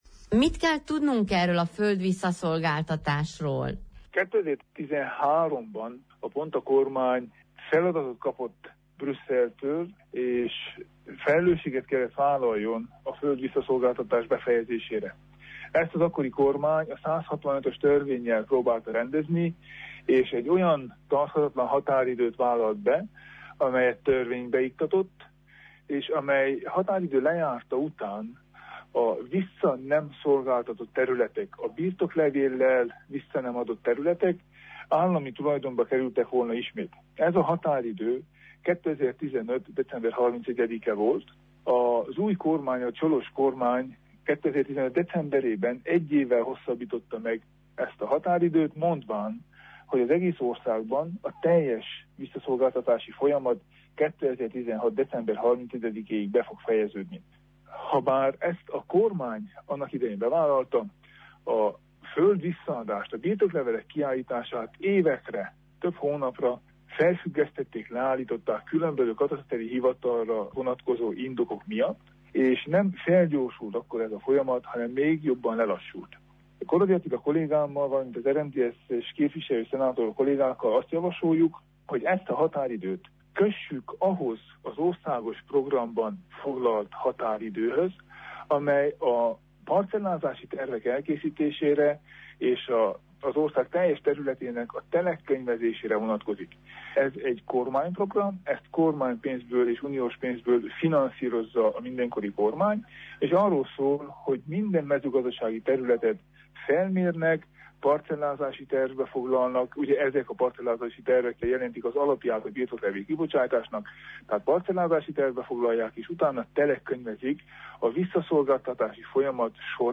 A törvényben az is szerepel, hogy a vissza nem szolgáltatott földterületek január elseje után az Országos Mezőgazdasági Földalapba kerülnek, amelyet az Állami Vagyonügynökség kezel majd. Tánczos Barna szenátort halljuk.